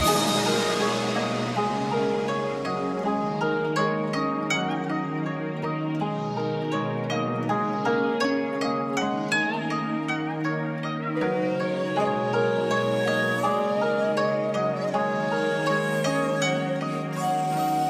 FOOLIN 161 BPM - FUSION.wav